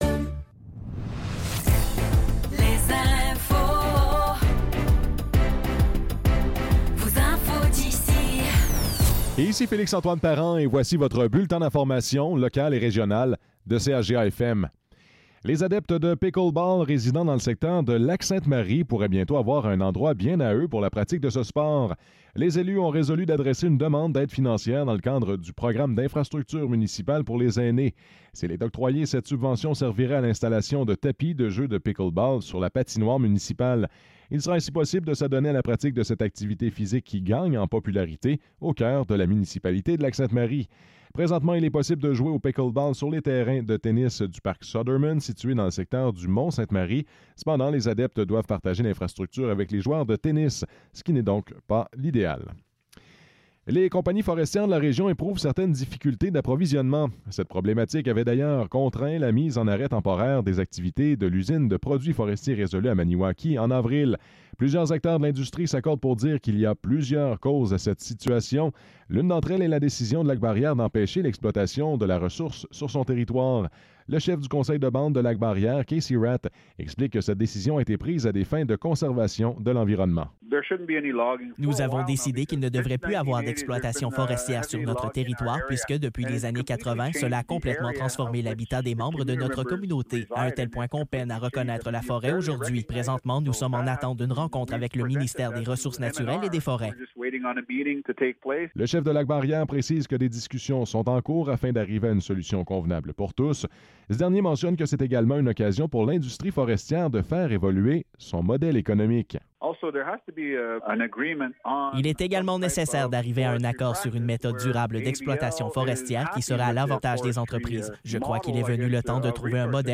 Nouvelles locales - 16 juillet 2024 - 10 h